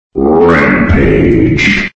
голосовые
из игр